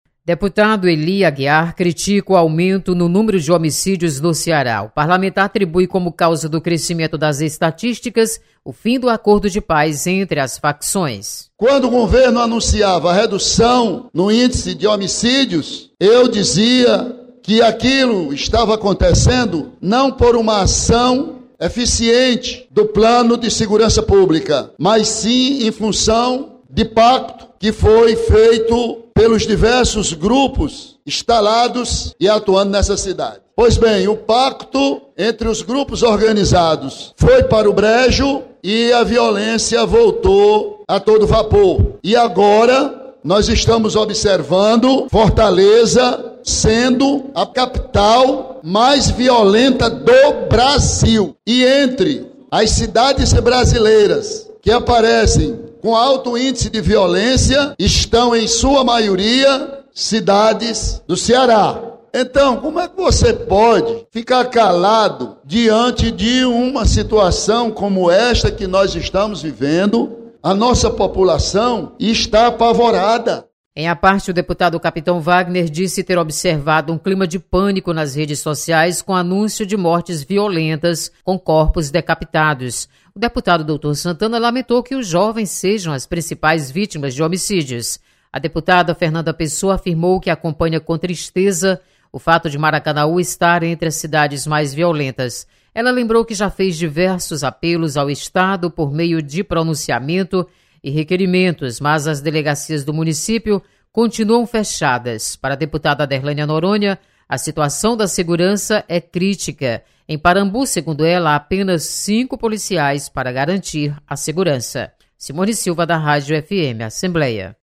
Deputados comentam sobre aumento do número de homicídios no Ceará.